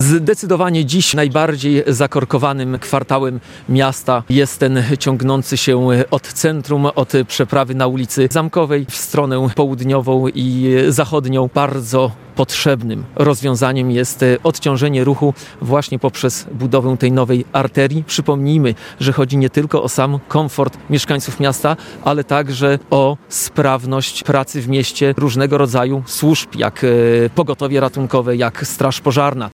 – To długo oczekiwana inwestycja, która wpłynie na upłynnienie ruchu w mieście – mówi prezydent Białej Podlaskiej Michał Litwiniuk.